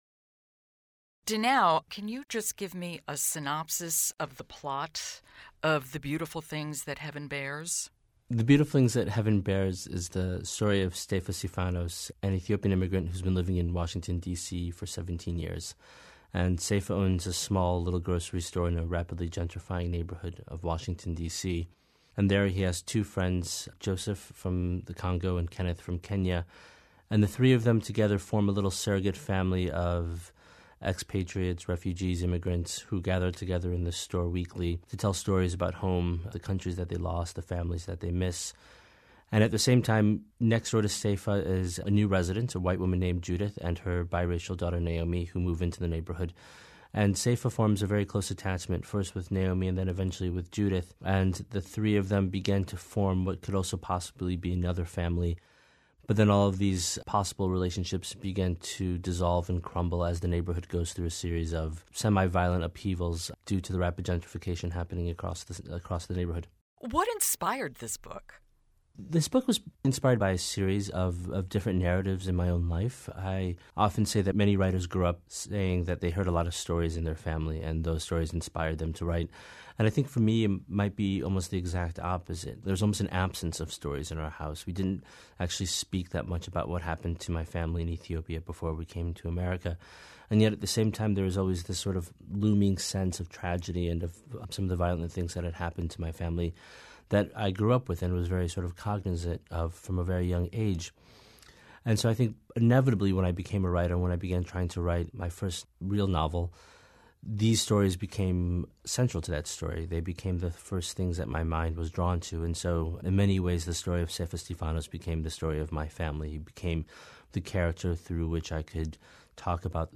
Big Read Interview with Dinaw Mengestu